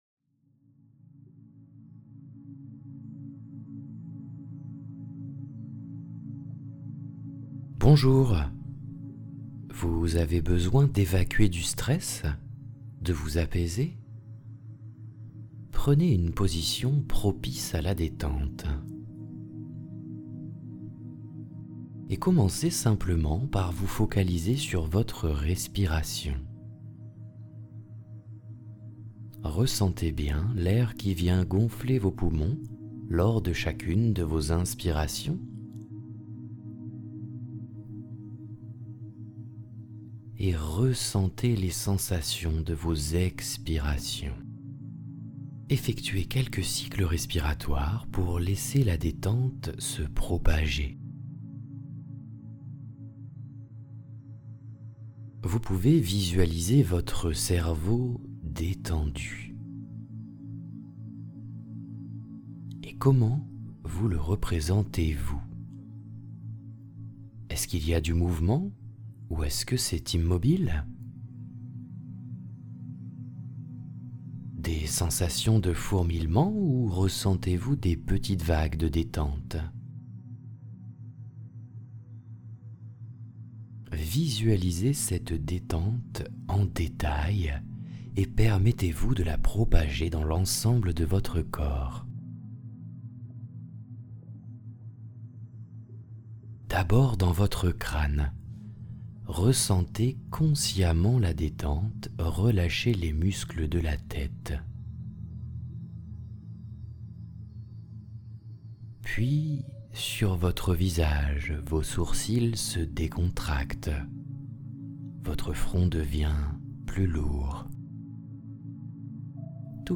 Hypnose relaxation.
Séance d'hypnose gratuite